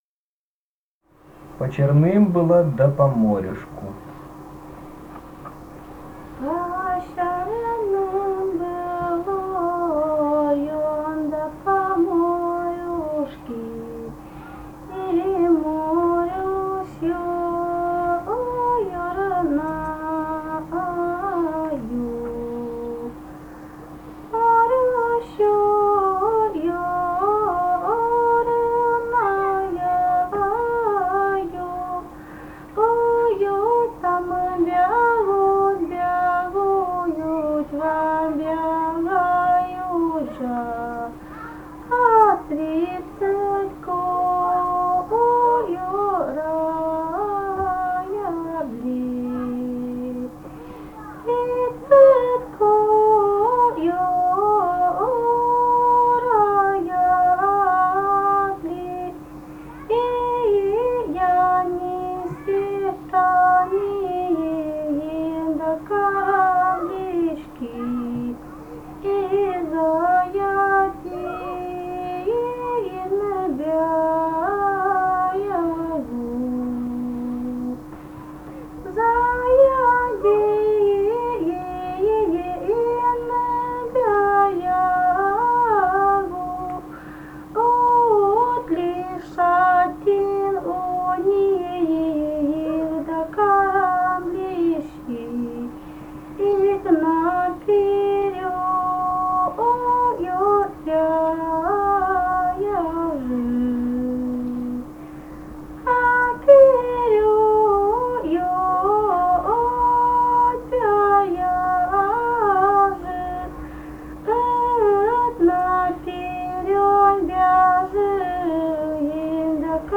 Этномузыкологические исследования и полевые материалы
Ставропольский край, с. Левокумское Левокумского района, 1963 г. И0724-13